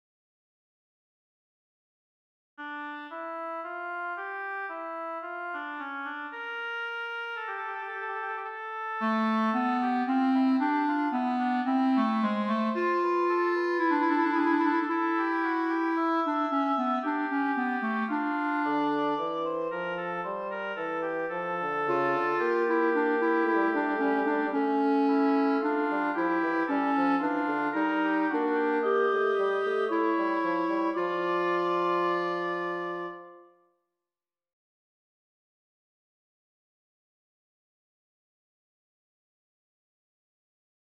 As you know, fugues begin with all the voices – from two to five in the case of the Well Tempered Clavier – in turn announcing the fugue theme; a process which is called the exposition. These announcements, however, don’t all have the same pitch or function and take the form of a kind of musical conversation, consisting of statements and answers.